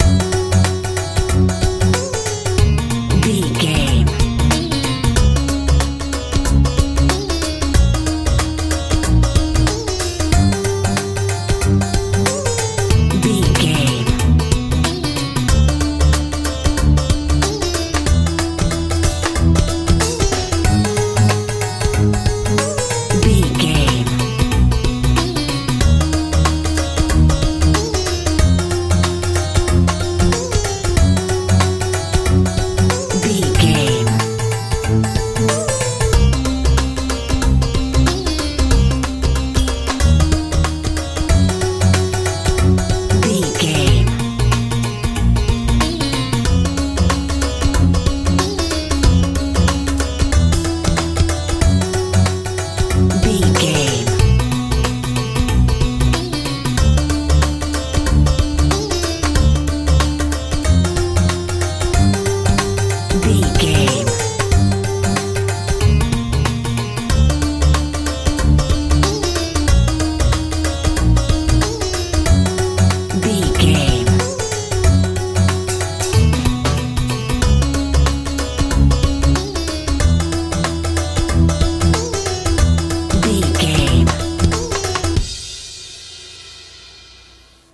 bollywood feel
Ionian/Major
F♯
dreamy
relaxed
violin
bass guitar
drums